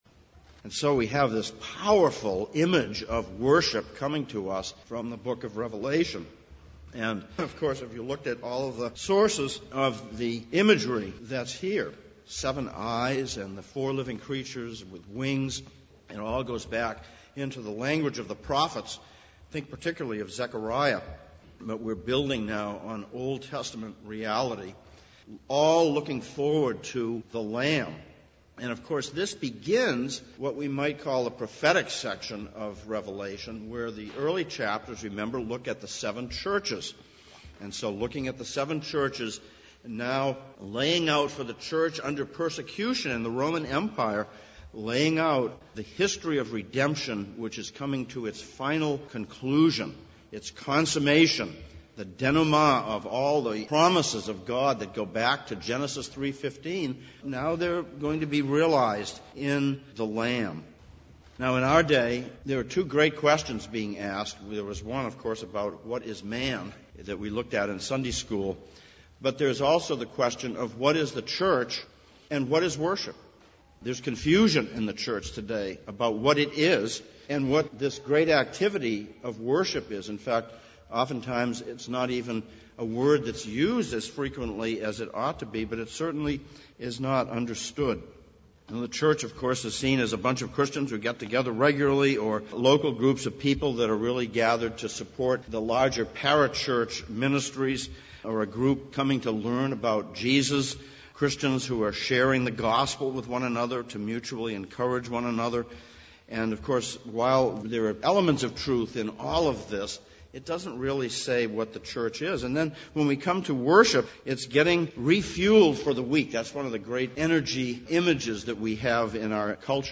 Worship Passage: Revelation 4:1-5:14, 1 Chronicles 29:10-20 Service Type: Sunday Morning « Of Creation